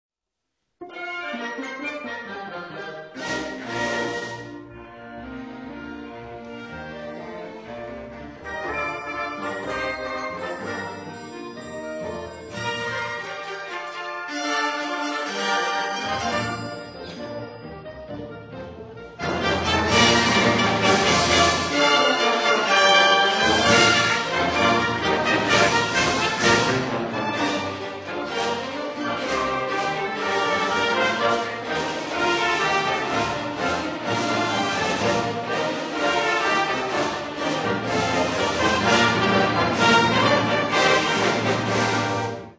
Concert on Sunday 25th June 2000
Peel Hall, Salford University